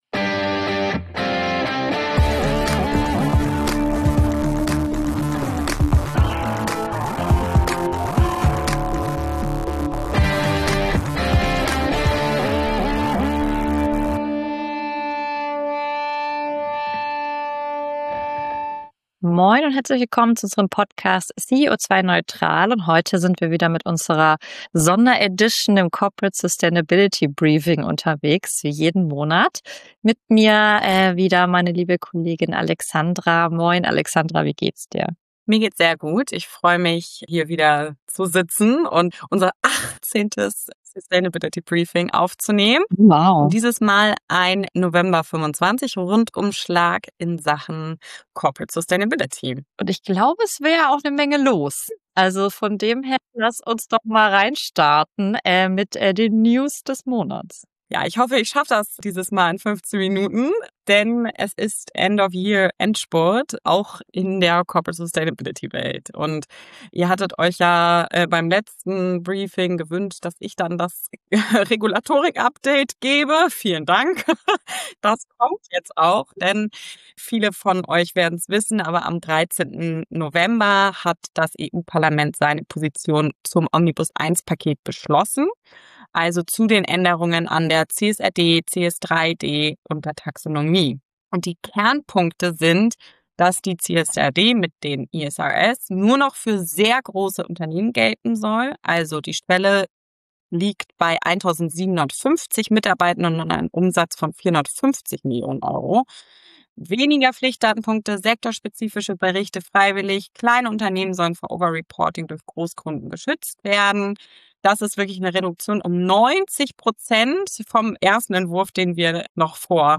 CEO2-neutral - Der Interview-Podcast für mehr Nachhaltigkeit im Unternehmen